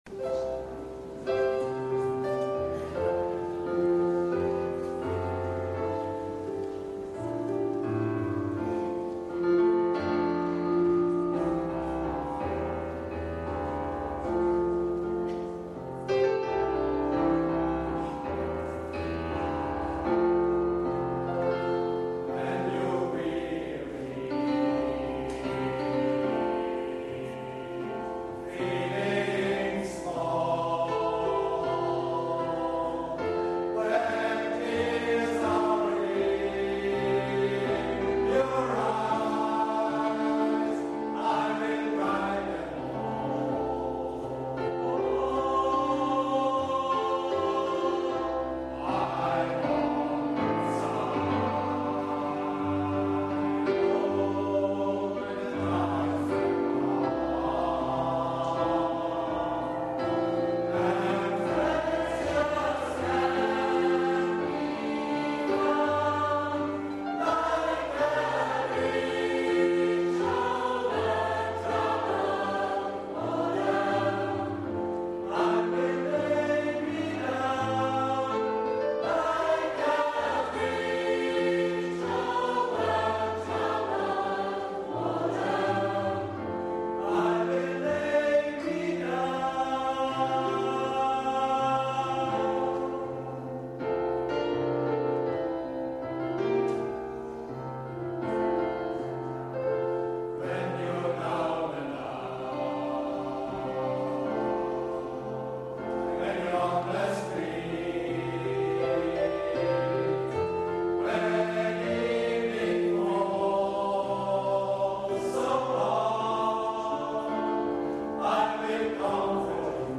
Live-Konzert vom 29. Oktober 2004 in der ref. Kirche Niederweningen
Piano, Drum